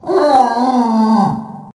zombie_die_0.ogg